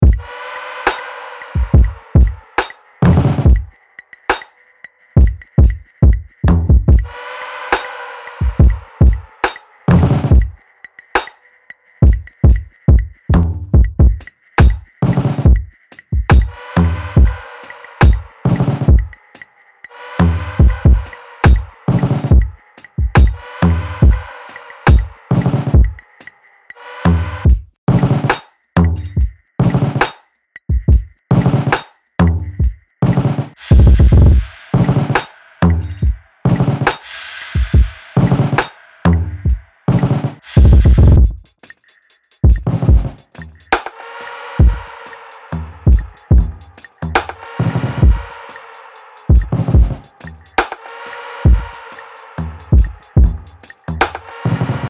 グルーヴィーなローファイ・バウンス、グリッドを超越するファンキー
・にじみ出るローファイ、遊び心、グリッドを超えるグルーブ感あふれるビート
プリセットデモ